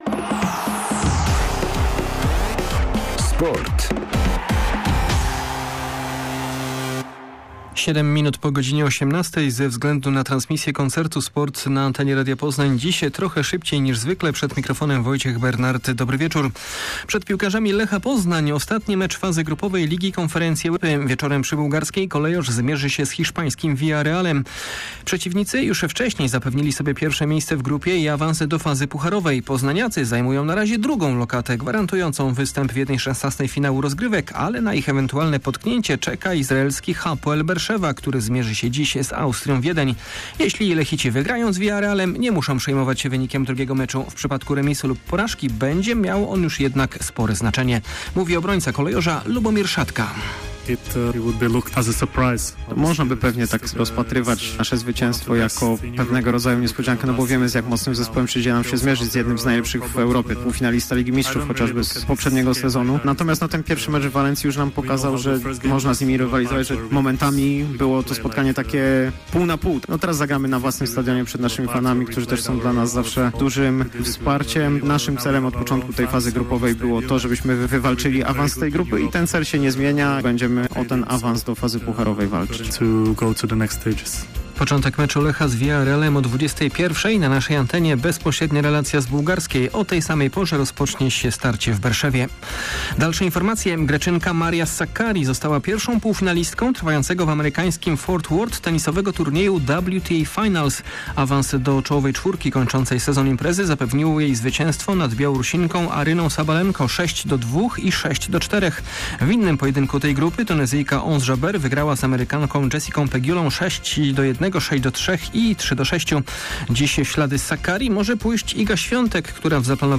03.11.2022 SERWIS SPORTOWY GODZ. 18:05